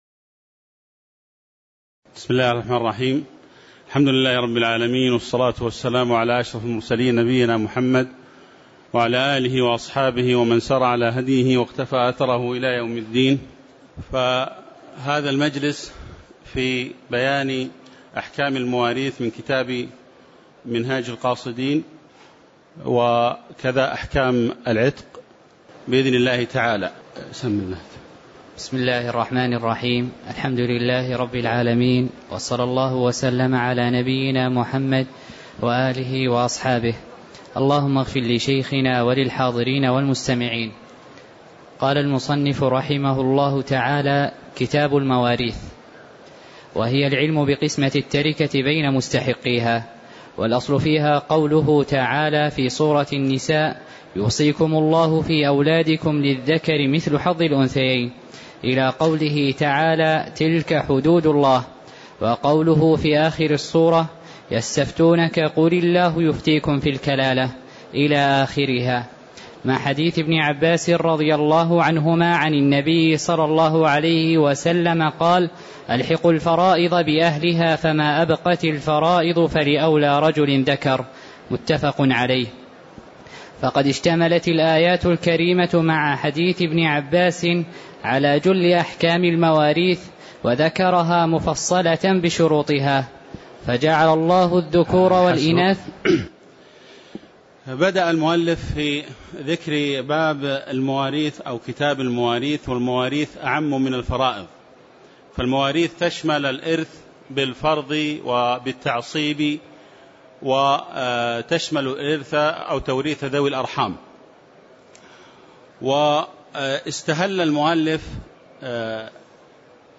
تاريخ النشر ٢١ شوال ١٤٣٧ هـ المكان: المسجد النبوي الشيخ